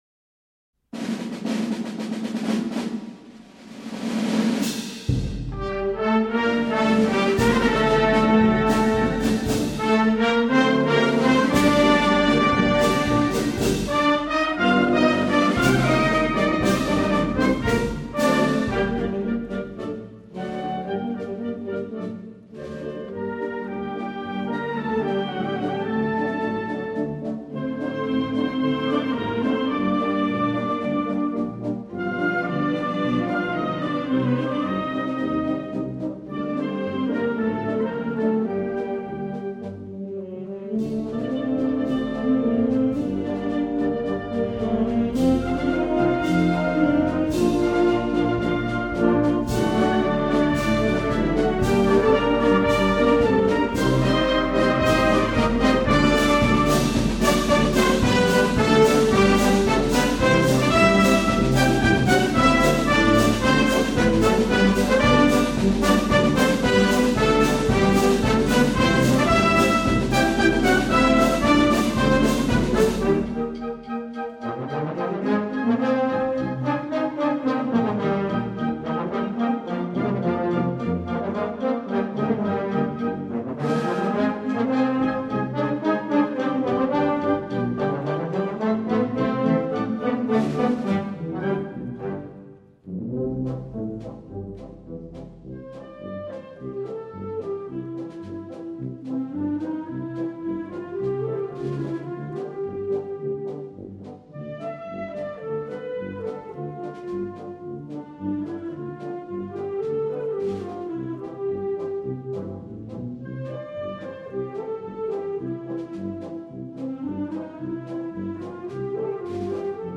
Himne
himno_avinguda_josep_pau.mp3